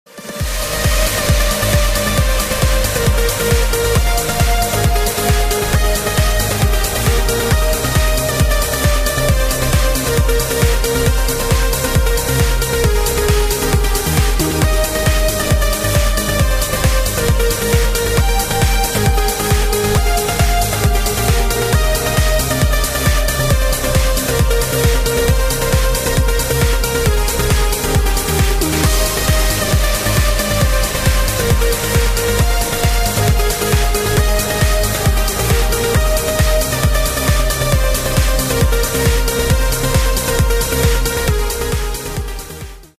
ритмичные
громкие
dance
Electronic
электронная музыка
без слов
club
Trance